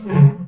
barnacle_grunt1.ogg